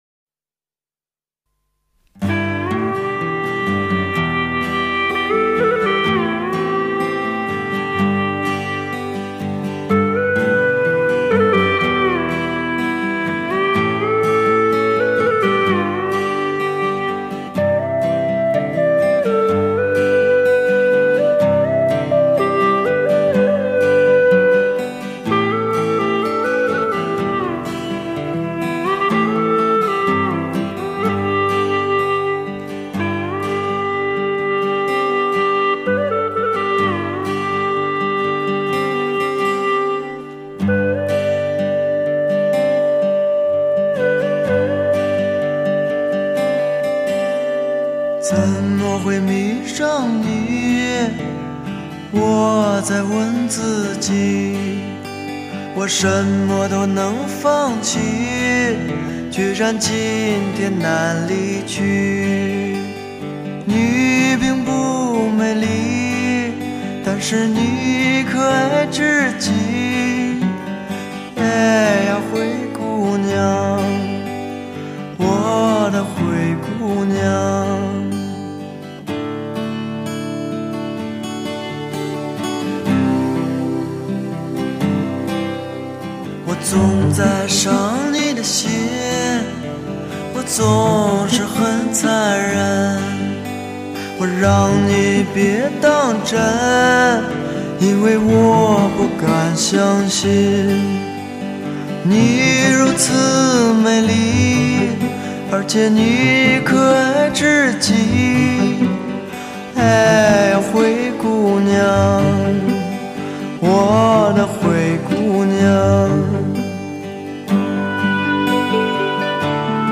异常独特的懒洋洋的哼唱唱腔和散发着迷人性感的理想主义歌词是这张专辑的最大特色。